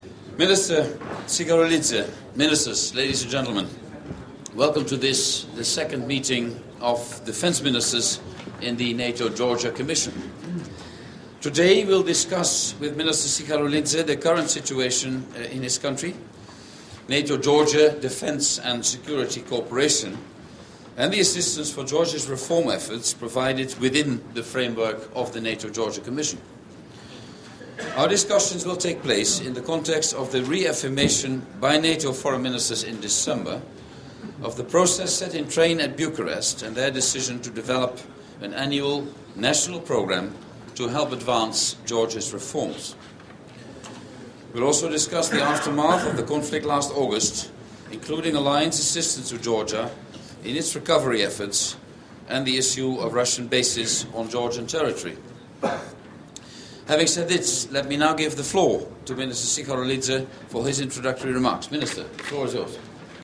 Press conference by the NATO Spokesman James Appathurai after the Informal Working Lunch of NATO Defence Ministers, with Invitees